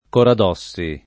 [ korad 0SS i ]